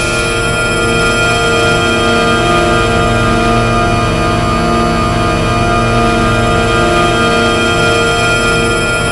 A#3 BOWED06R.wav